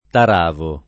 [ tar # vo ]